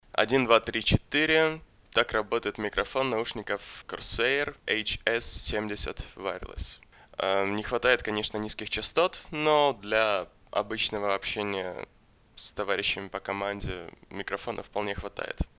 Микрофон лучше всего использовать на расстоянии 2.5 — 5 см от рта.
Не хватает низких частот. Да и в целом качество средненькое.
Микрофон HS70 WIRELESS Gaming Headset:
Лучше докупить поп фильтр на микрофон, стоит копейки. Убирает взрывные согласные.
hs70-wireless-gaming-headset.mp3